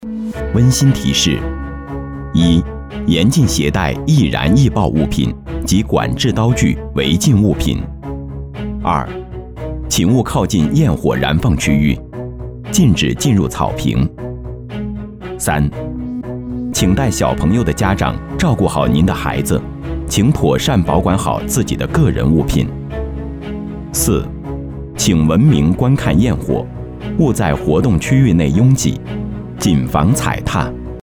男声 Male Voice-公司名
男S386 语音提示-温馨提示-沉稳亲切